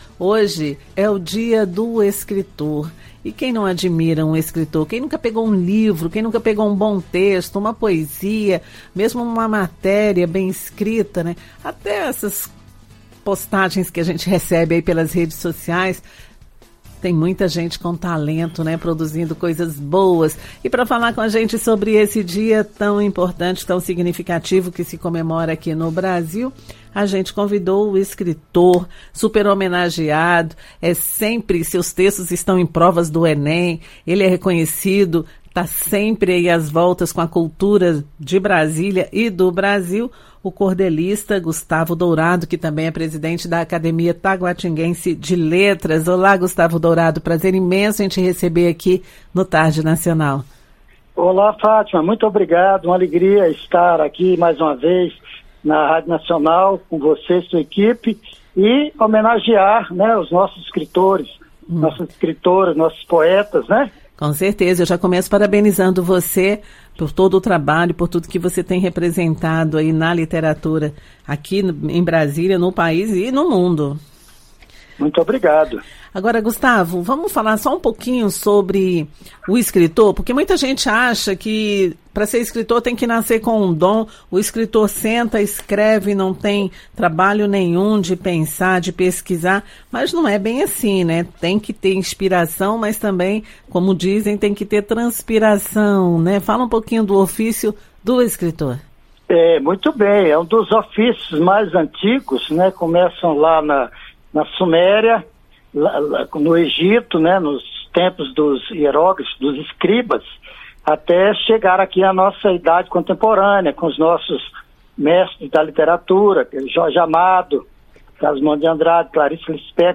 Ele afirmou que o Brasil é um celeiro de escritores e que é preciso trabalhar muito na profissão. Ouça a entrevista: